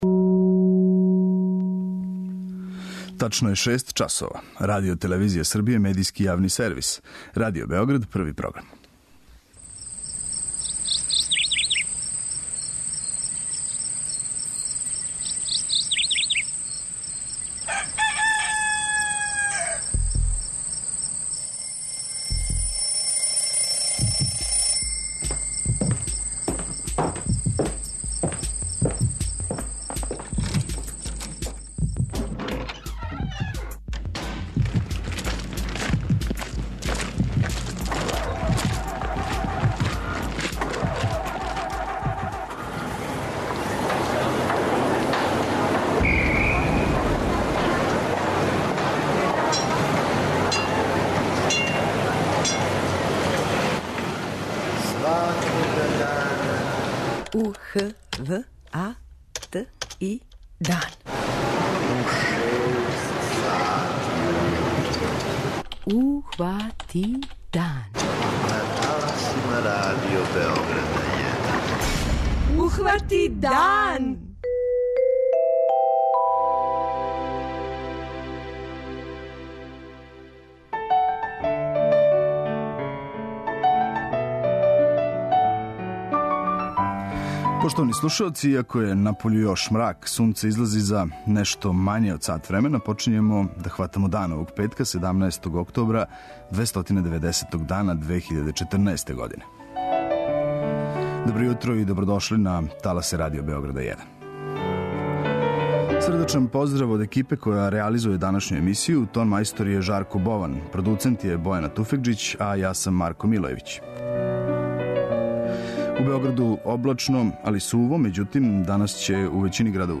Као и сваког петка, док хватамо дан, имаћемо и квиз.
преузми : 85.92 MB Ухвати дан Autor: Група аутора Јутарњи програм Радио Београда 1!